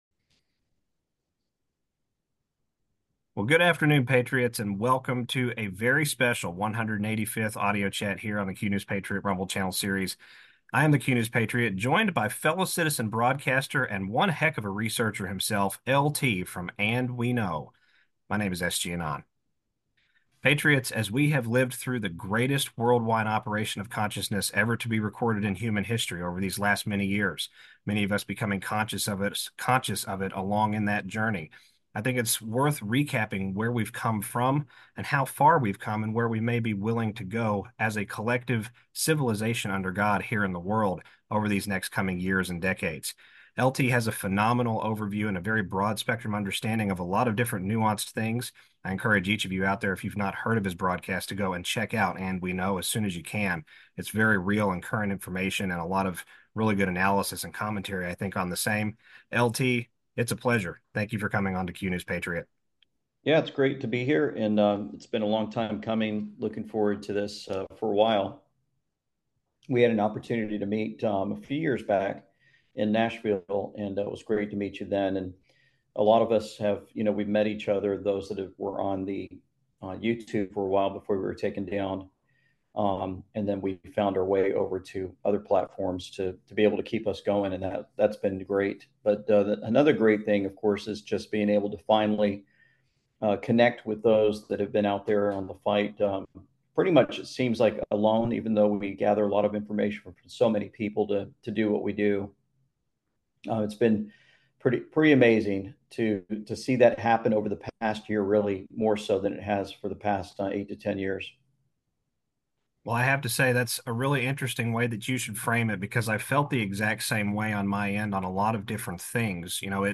In this audio chat, a former Marine shares his inspiring journey from military service to becoming a successful podcaster. He talks about learning to write for newspapers, running a TV station in Japan, and handling media during a tsunami relief operation. His story highlights the power of faith, patience, and staying informed about global events.